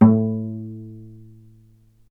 vc_pz-A2-ff.AIF